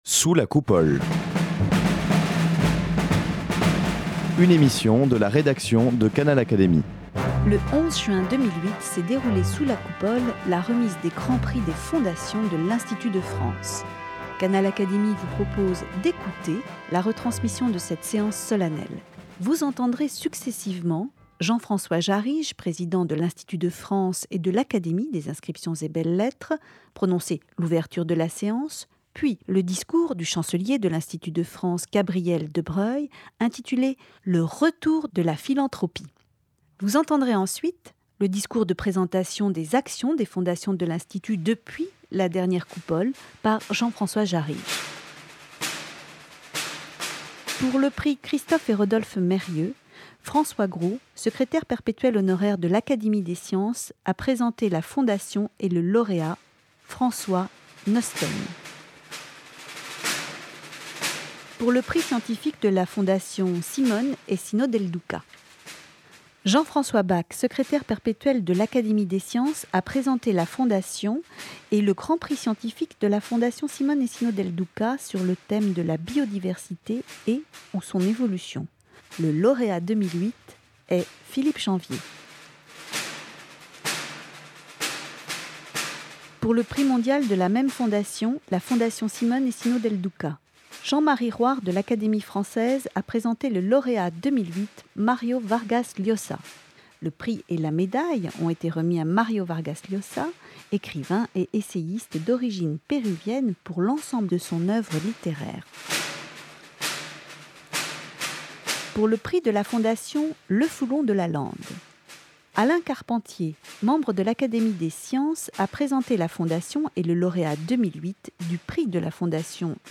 Le 11 juin, s'est déroulée sous la Coupole, la remise des Grands Prix des fondations. Canal Académie vous propose d'écouter la retransmission de cette séance solennelle.
Vous entendrez successivement un discours de Gabriel de Broglie, chancelier de l'Institut de France, un discours de Jean-François Jarrige et le son d'un reportage filmé sur l'activité des jurys auxquels se consacrent les académiciens.